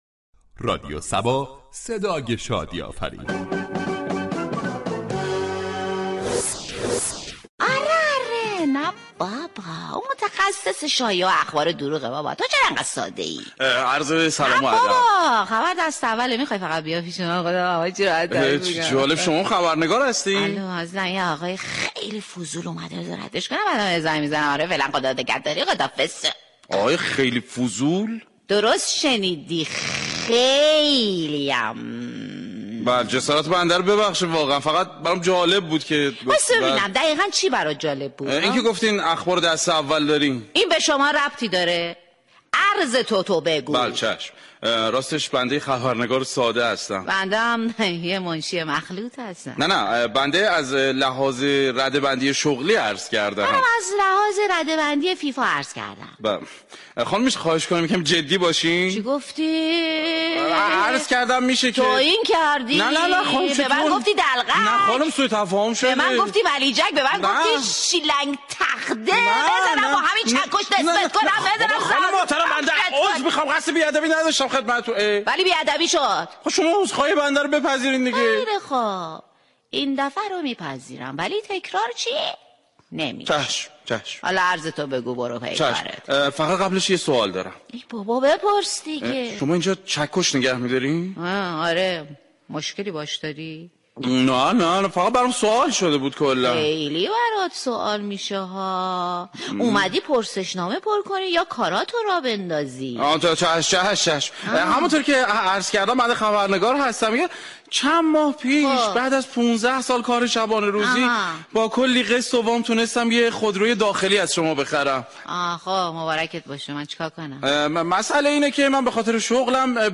"شهر فرنگ" با پخش ترانه های نشاط آفرین به همراه آیتم های نمایشی كوتاه با بیان طنز به نقد مسایل سیاسی ، اقتصادی، ورزشی و اجتماعی میپردازد.
شهر فرنگ در بخش نمایشی با بیان طنز به موضوع "مشكلات و نقص های خودروهای صفر "پرداخته است ،در ادامه شنونده این بخش باشید.